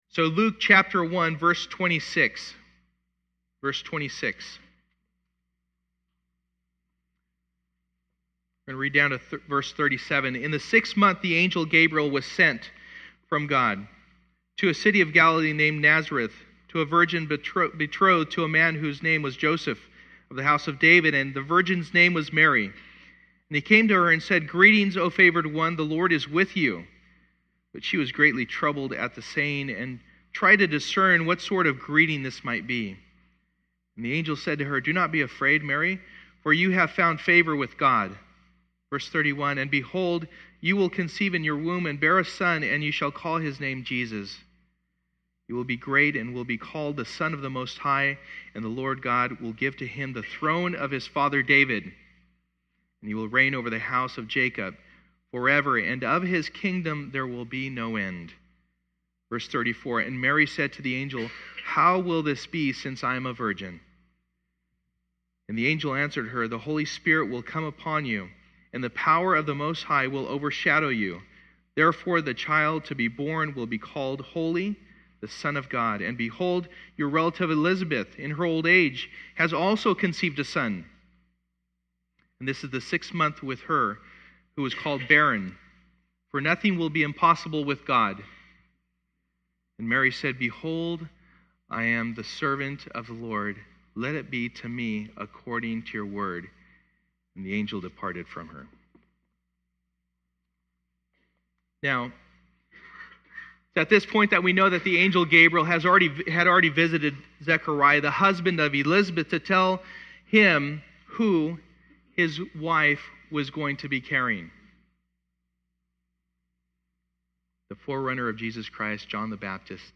Luke 1:26-55 Service: Sunday Morning Luke 1:26-55 « We Have Work to Do Face Down